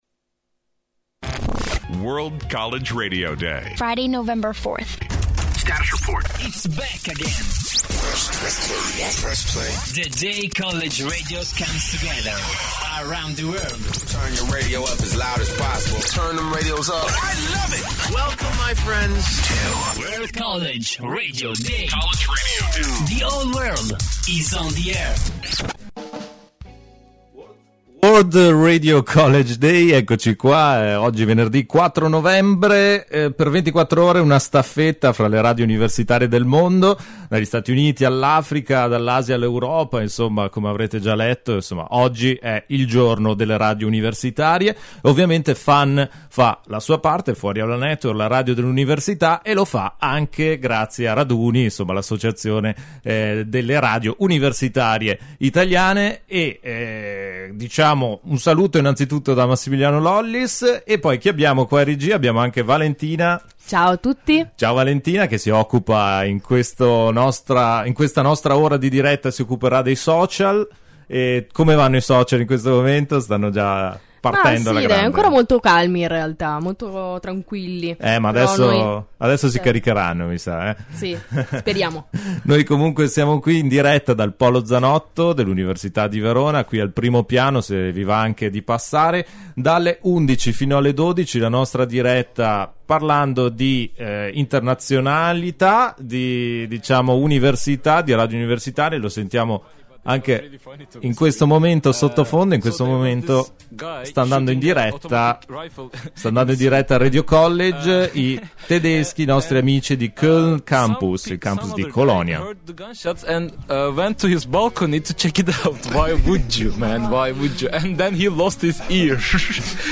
WCRD16 la diretta di FAN - FuoriAulaNetwork